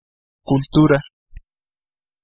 Ääntäminen
France: IPA: [kyl.tyʁ]